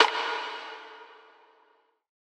Too Late Perc.wav